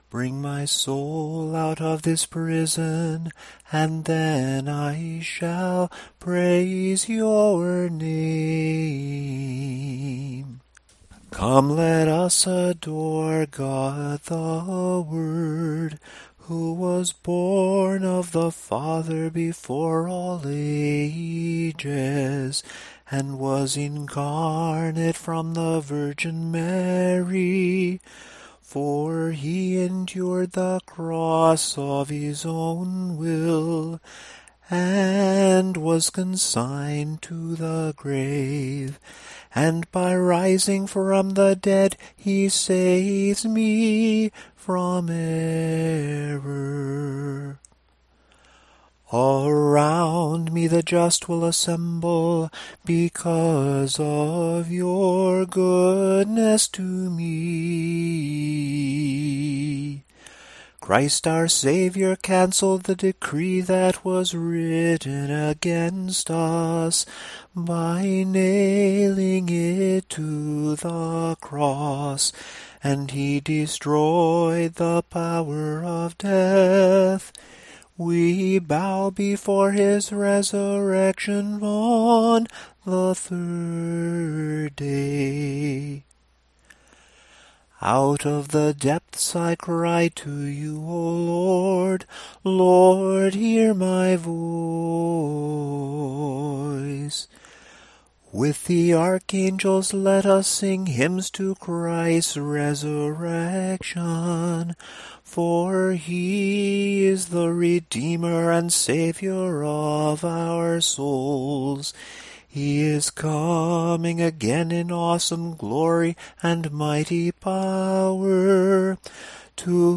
After a series of recited (i.e., simply chanted) verses, we begin singing the stichera. Here are the first four Sunday stichera of the Resurrection in Tone 2, together with the psalm verses that would ordinarily come before each one.
Tone_2_samohlasen_Sunday_stichera.mp3